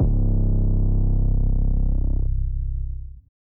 kits/TM88/808s/808 from my song 888.wav at ts